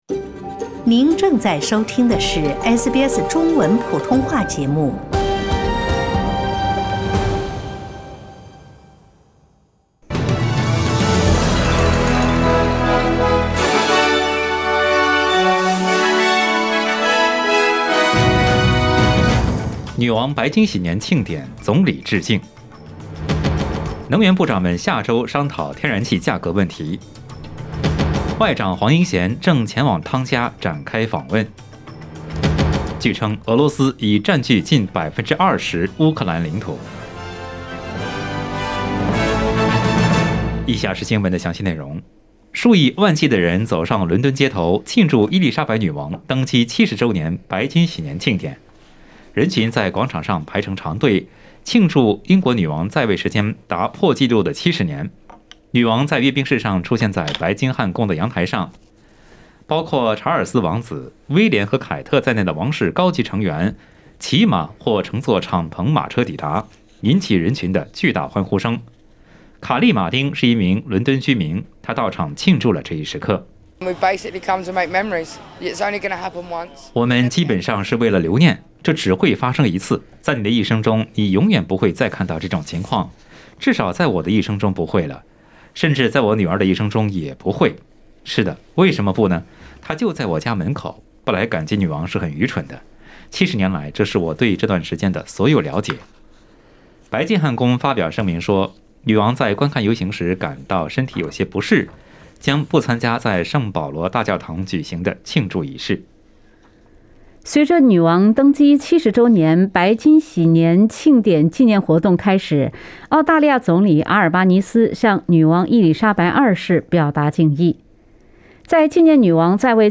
SBS早新闻（6月3日）